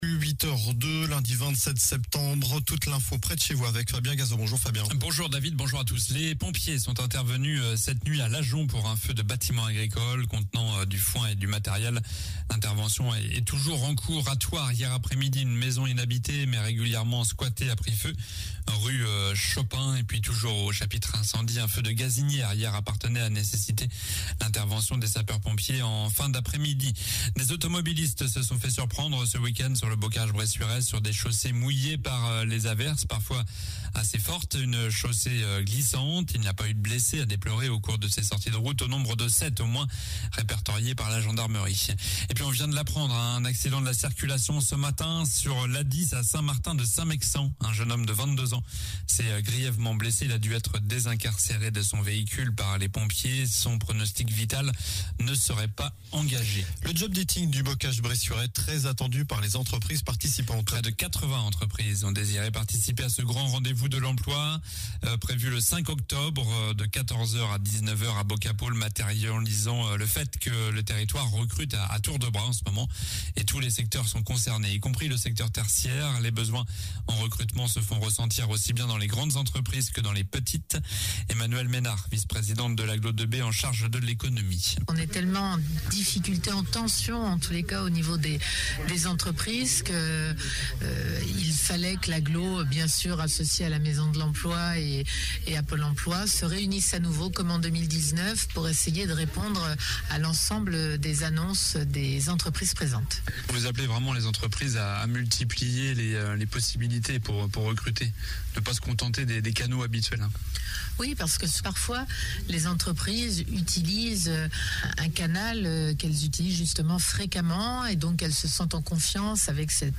Journal du lundi 27 septembre (matin)